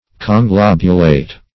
Search Result for " conglobulate" : The Collaborative International Dictionary of English v.0.48: Conglobulate \Con*glob"u*late\, v. i. [Pref. con- + globule.]
conglobulate.mp3